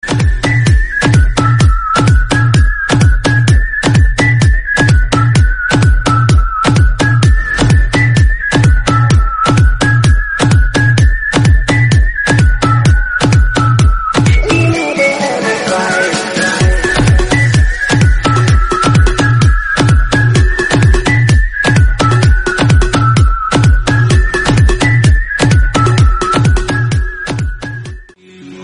Whistle-Of-Near-Tree-By-The-River.mp3